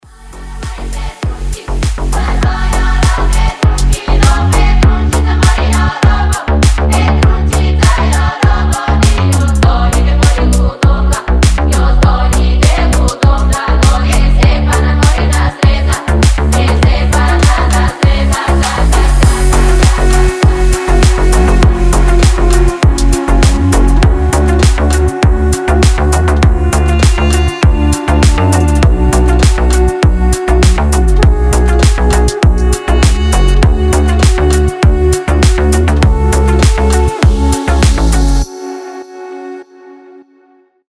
• Качество: 320, Stereo
deep house
атмосферные
Народные
этнические